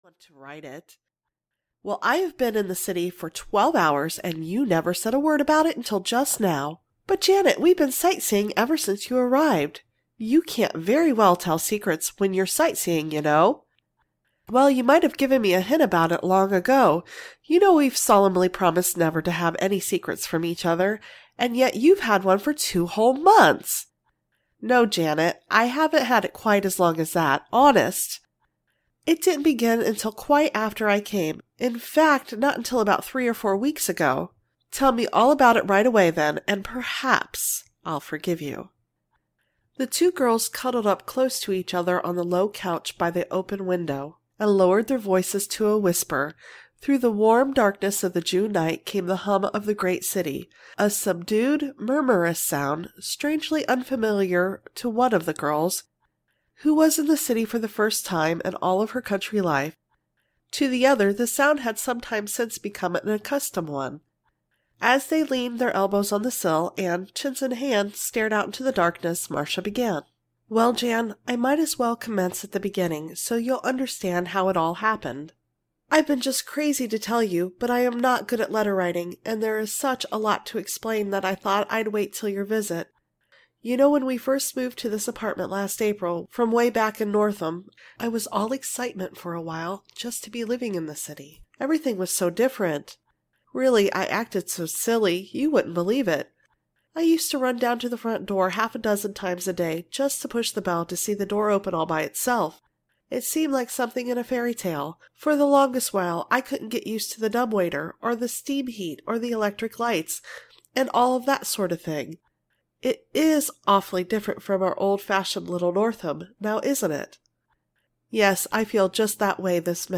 The Girl Next Door (EN) audiokniha
Ukázka z knihy